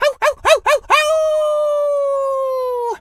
pgs/Assets/Audio/Animal_Impersonations/wolf_howl_04.wav at master
wolf_howl_04.wav